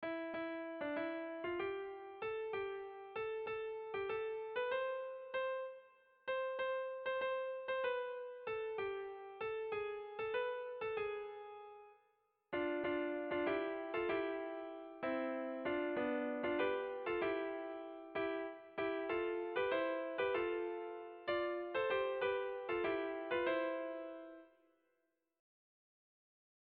Kontakizunezkoa
Zortziko txikia (hg) / Lau puntuko txikia (ip)
ABDE